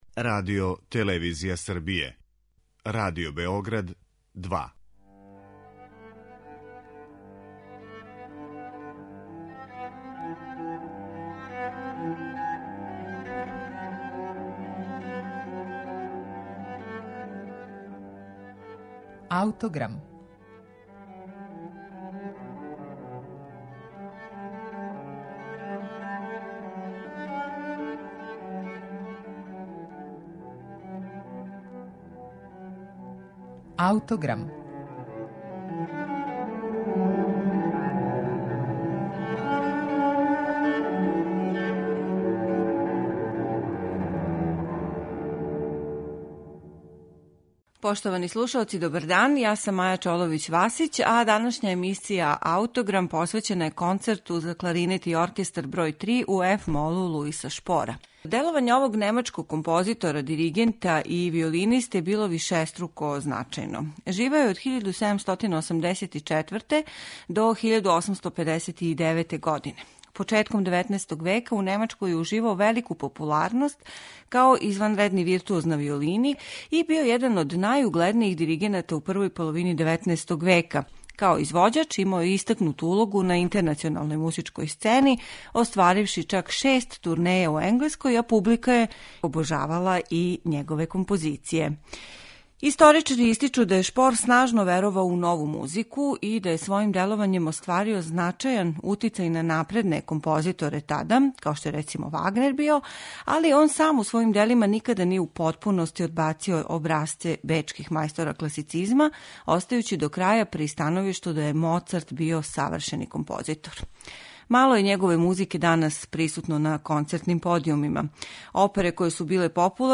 Трећи концерт за кларинет и оркестар Луиса Шпора